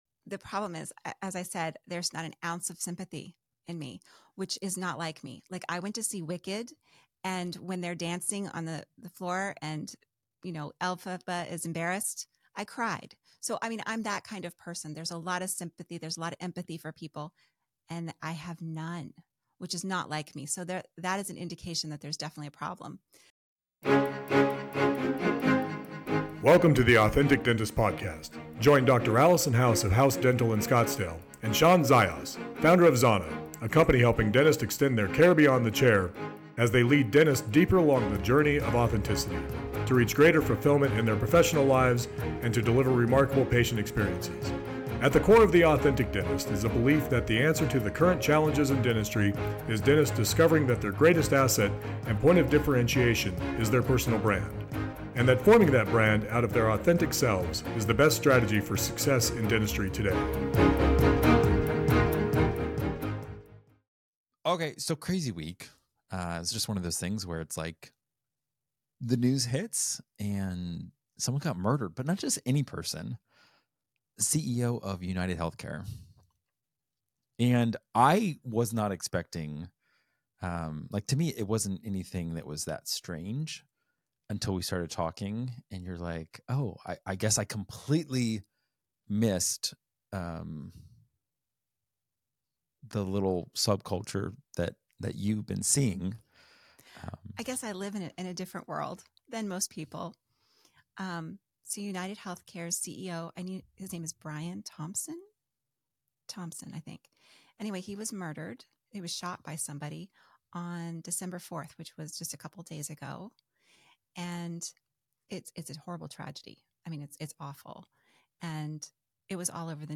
In this sobering discussion